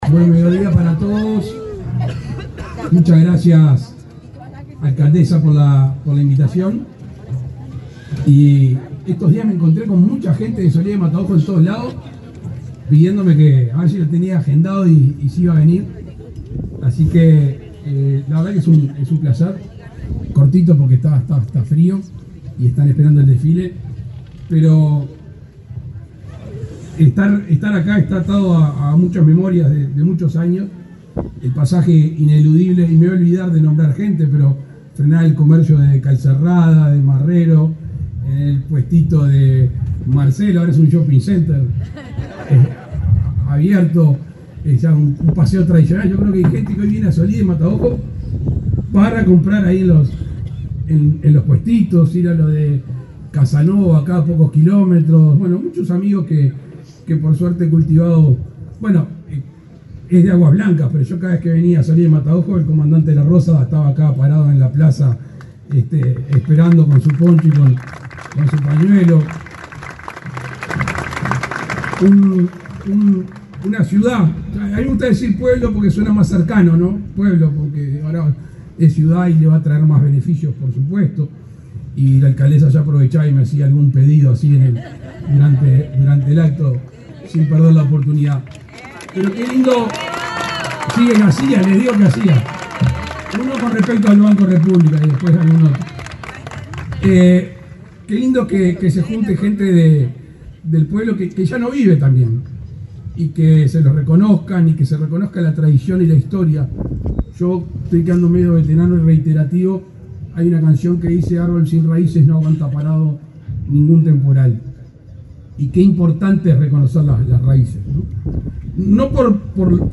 Palabras del presidente Luis Lacalle Pou | Presidencia Uruguay
Este lunes 12, el presidente de la República, Luis Lacalle Pou, participó en los festejos por el 150.° aniversario de la localidad de Solís de Mataojo